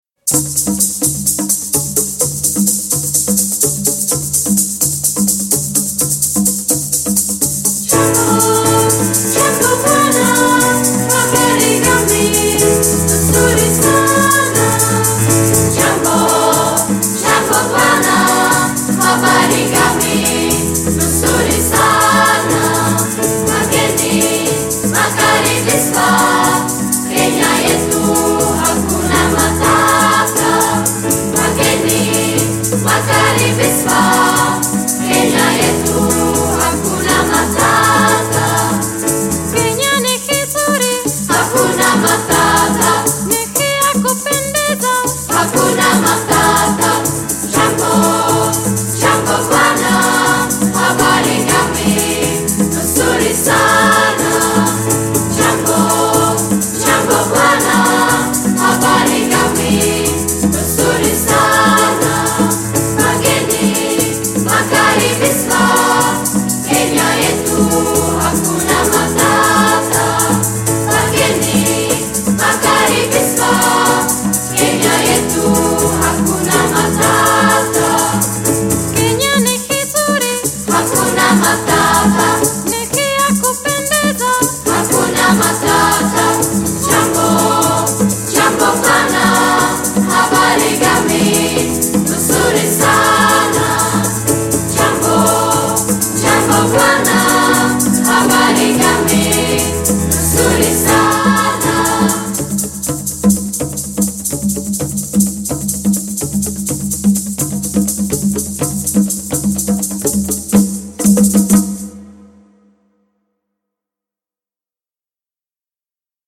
Pěvecké sbory